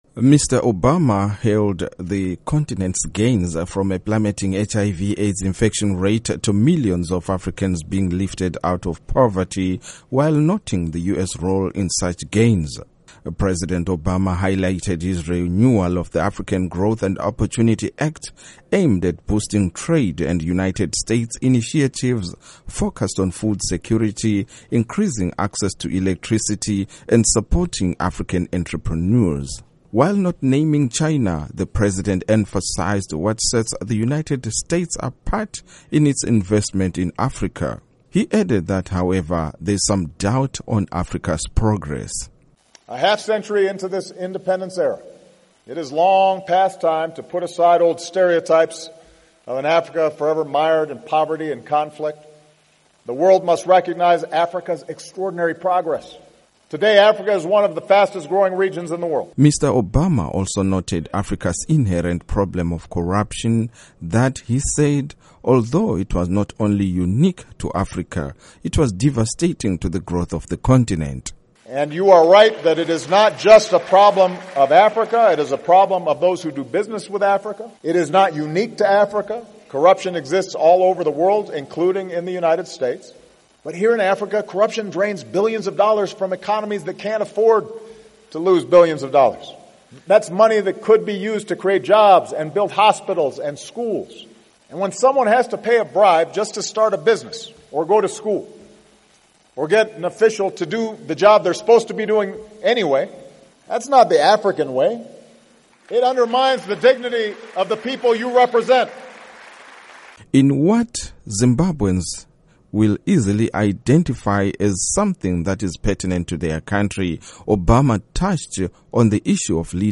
Reports on President Barack Obama's Speech While in Ethiopia